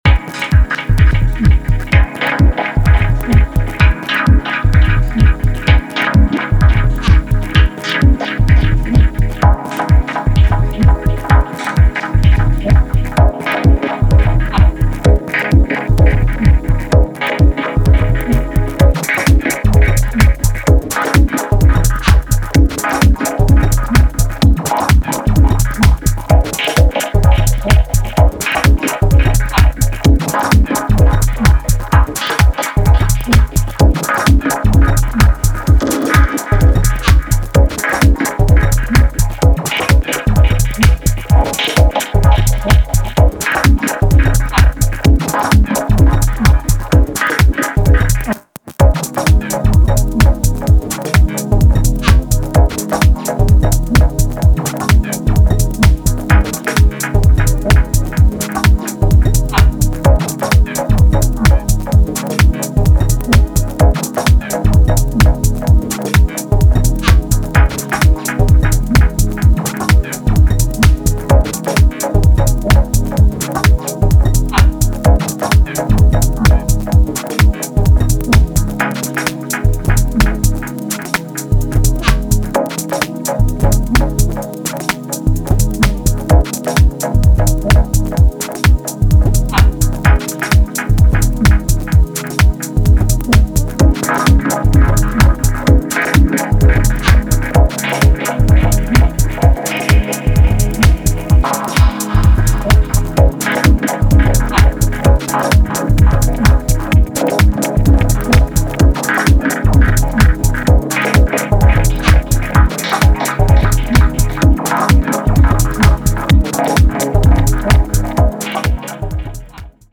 幻想的なコードの中でさりげにエグいFXが展開するディープ・ハウス/ミニマル・ダブテック
深い没入感に加えクラシカルなディープ・ハウスのエネルギーも感じられますね。